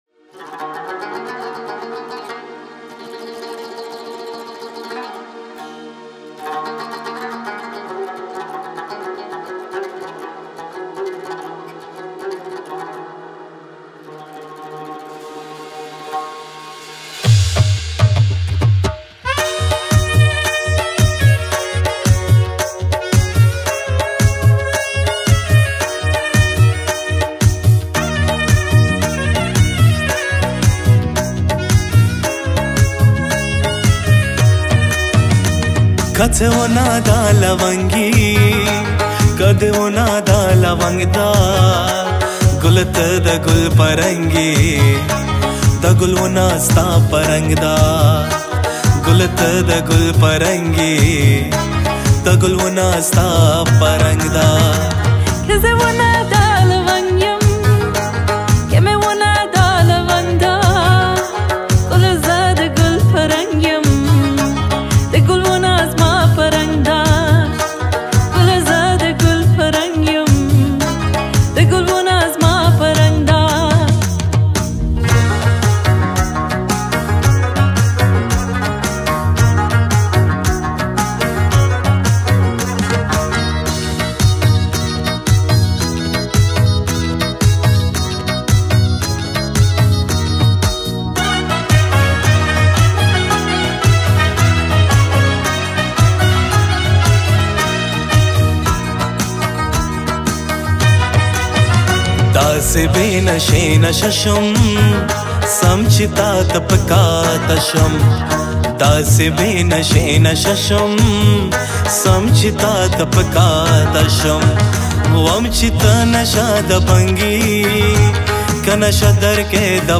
duet
Compose: Folk